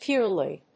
パーロイド